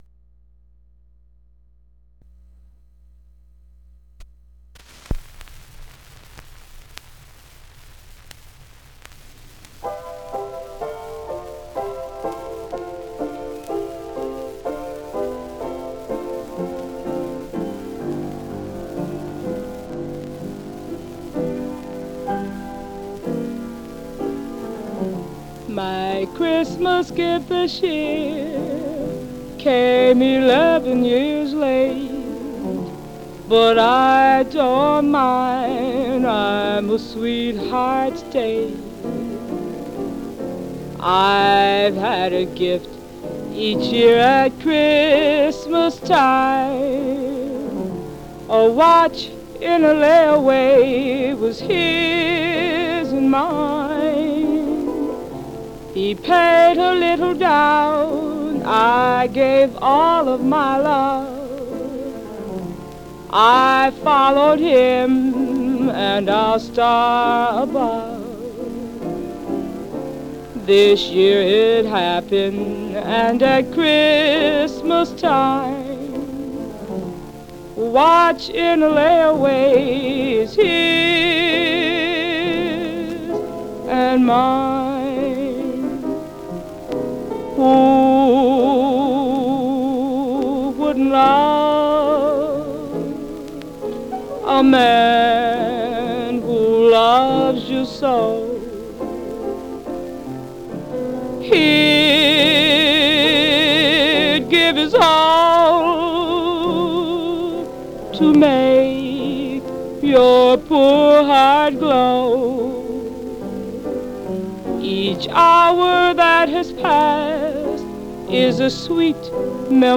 Stereo/mono Mono
Ballad
Jazz